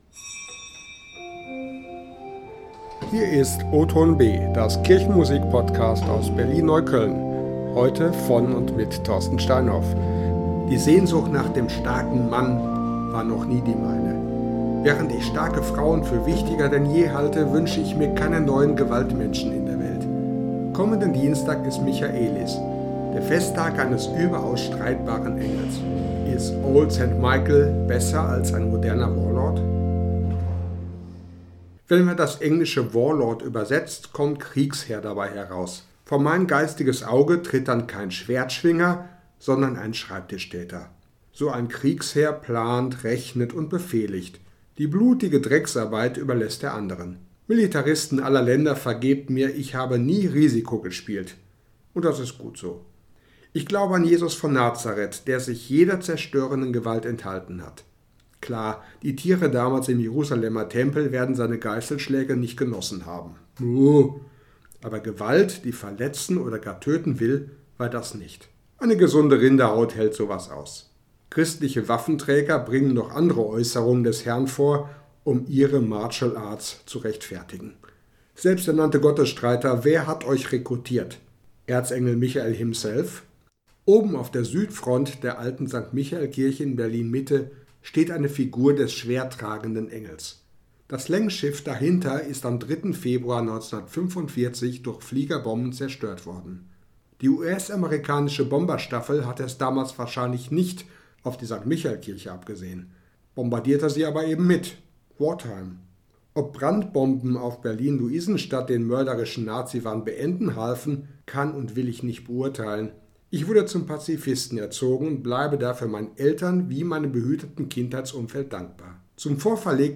• Wortbeitrag: Hauen bitte nur auf dickes Fell
• Musik: Unüberwindlich starker Held (Lied Nr. 606 im alten Gotteslob, gespielt an der Sauer-Orgel in St. Michael Berlin Mitte)